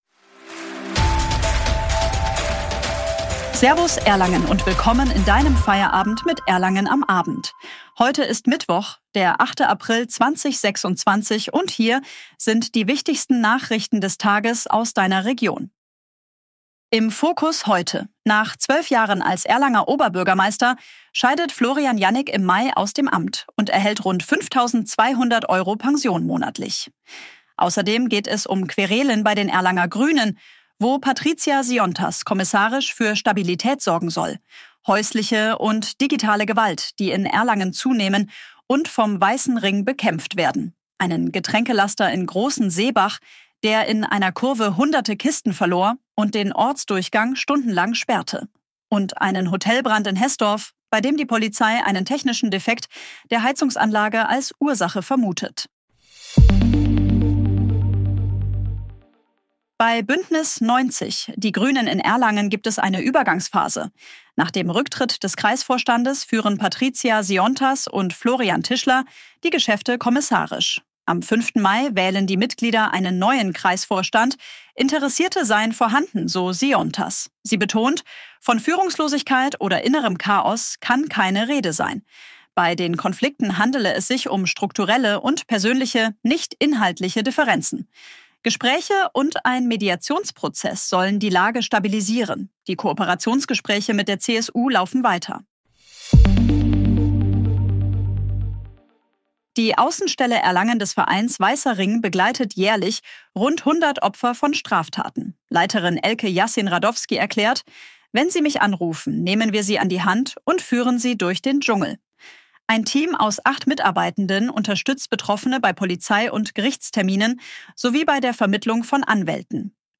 Willkommen zu deinem täglichen News-Update